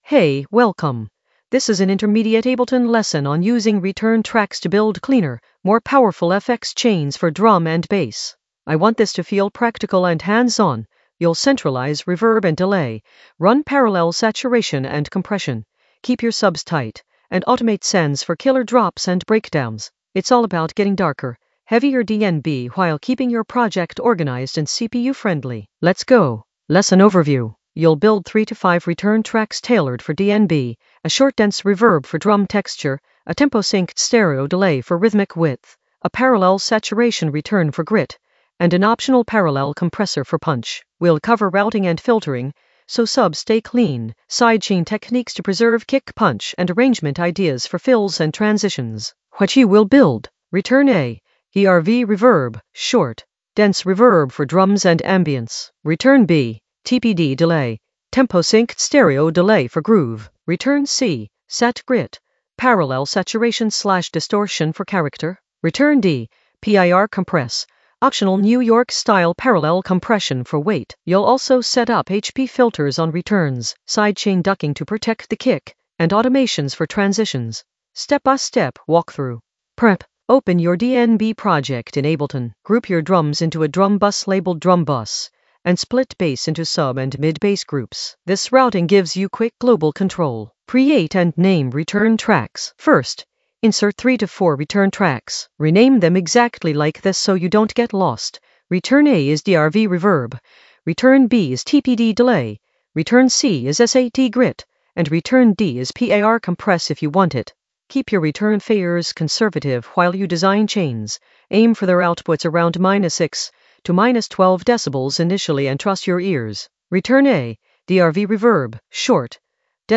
Narrated lesson audio
The voice track includes the tutorial plus extra teacher commentary.
An AI-generated intermediate Ableton lesson focused on Using return tracks for cleaner FX chains in the Workflow area of drum and bass production.